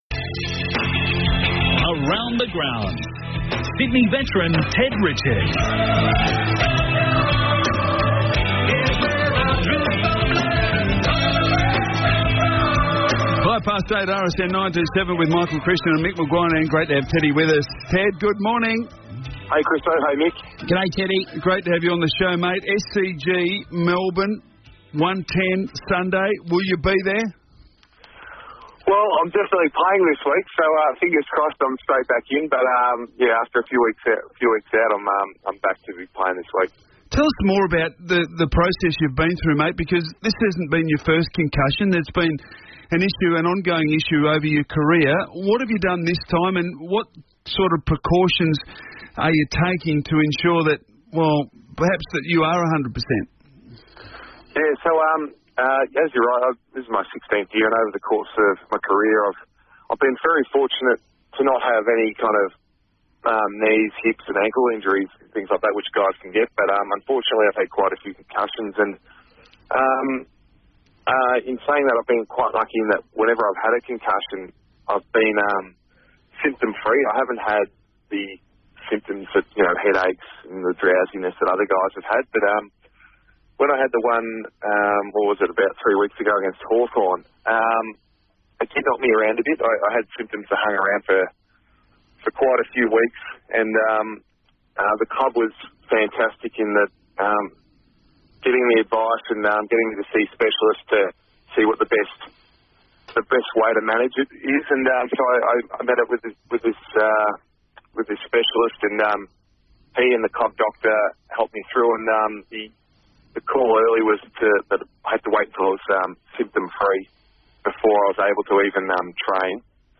Ted Richards joins the boys on Radio Sport National for his regular segment.